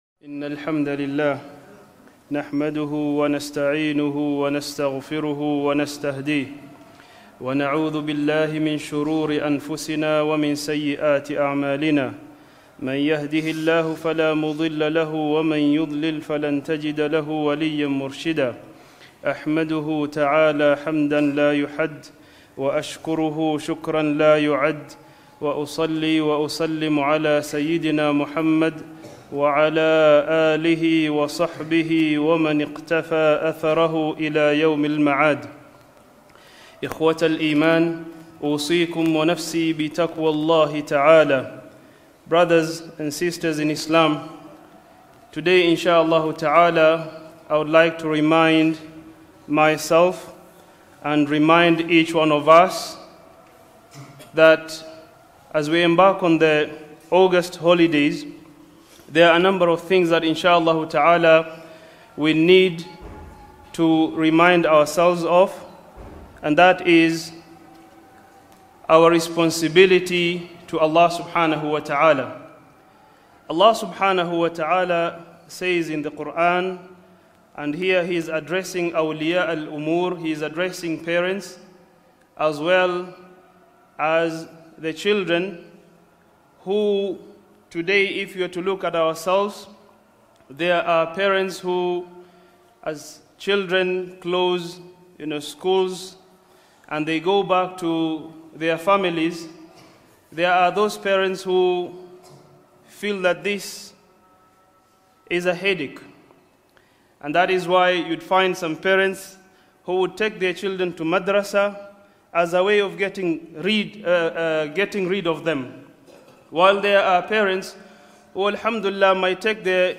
Khutbah